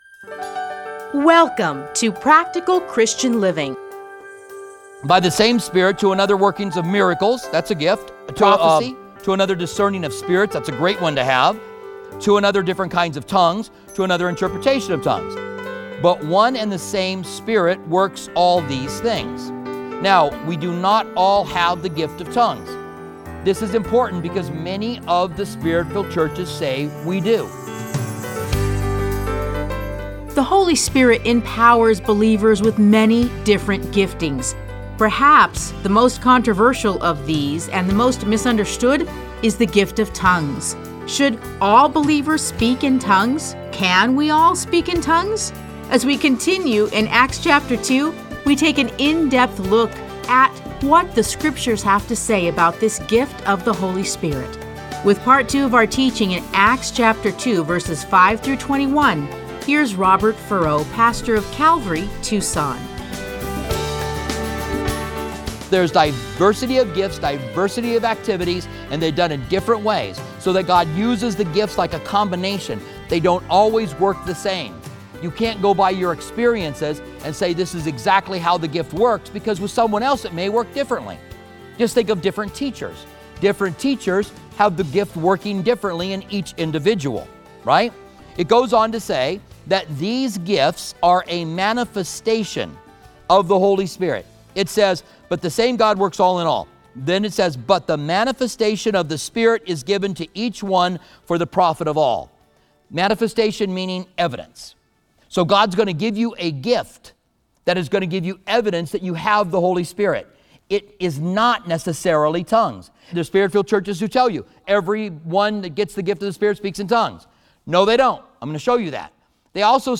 Listen to a teaching from Acts 2:5-21.